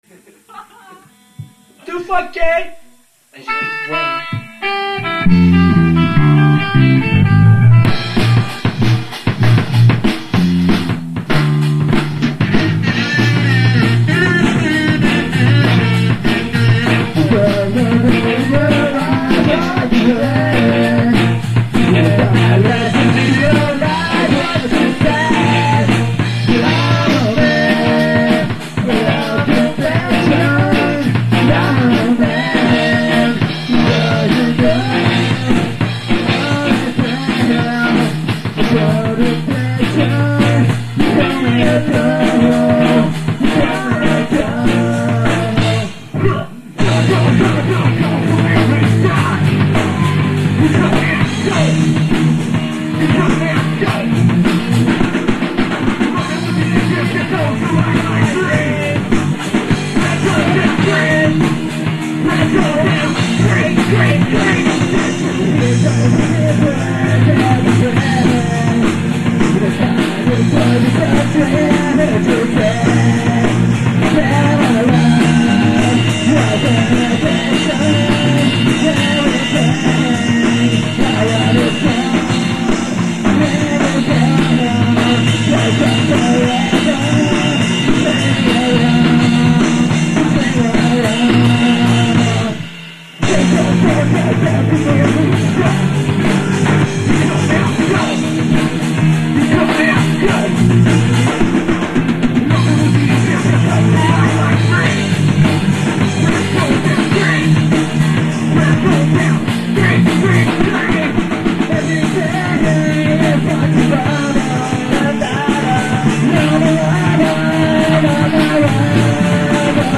Cover
enregistré à l'aide du radio portatif lors d'une prarique.